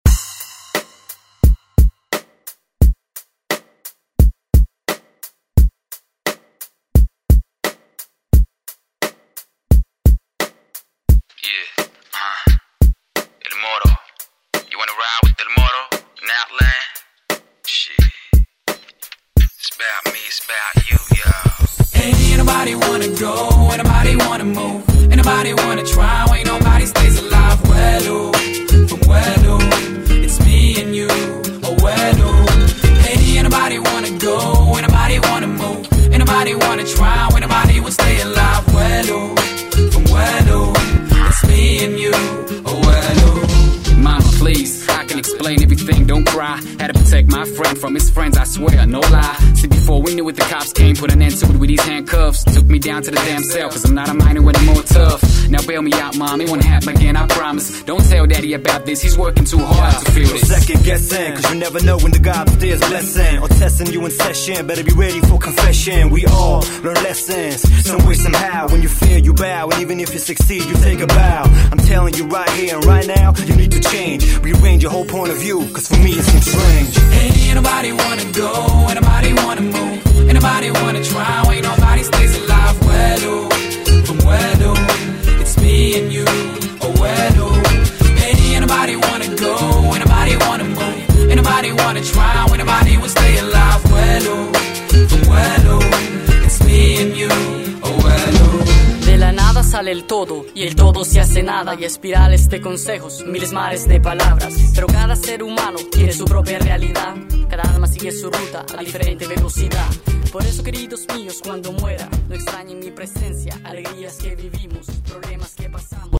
Genre: 90's